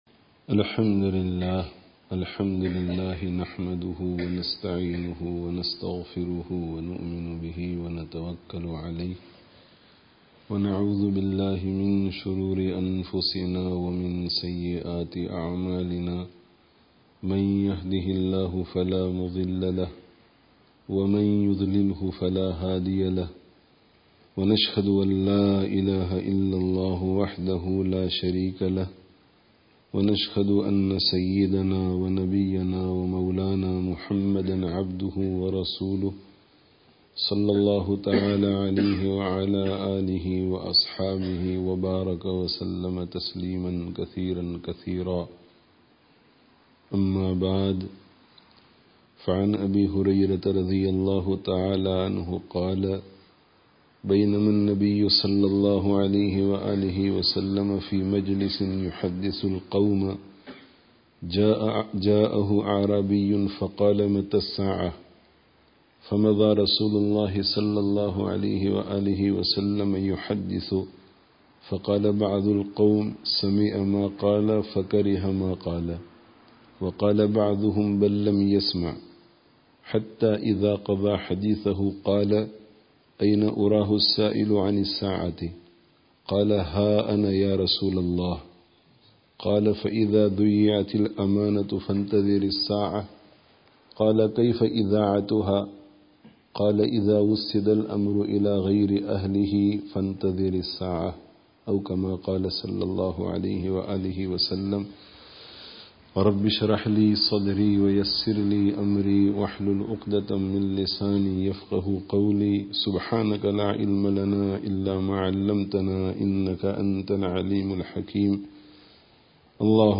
Dars of Hadith